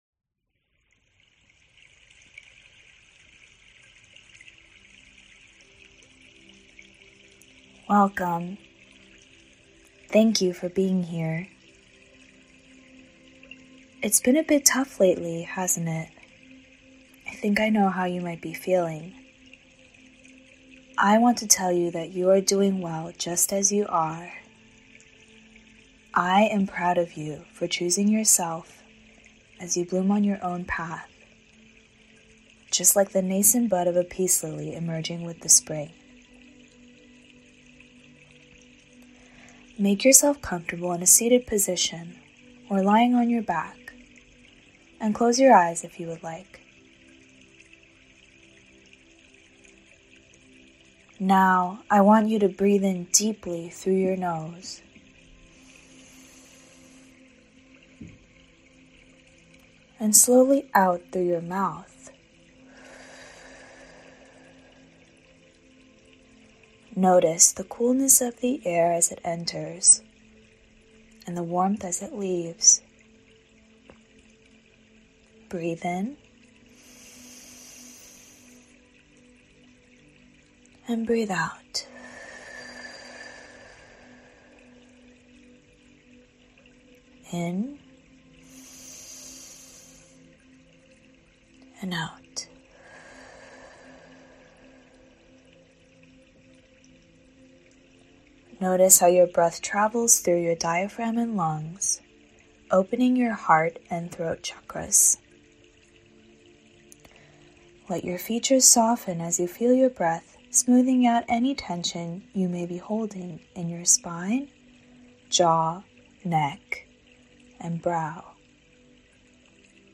Guided Meditation for Mindful Listening to the Self, with musical vignettes and suggested plant pairings
A 15-minute meditation that guides the individual towards a more elevated and clarified state of being that cultivates awareness, self-love and gratitude, and compassion for all beings. This practice emphasizes the deep listening typically attributed to musicians in the midst of their craft, using musical interludes to allow the listener to enter a state of heightened meditative flow.
Guided Meditation for Mindful Listening to the Self.mp3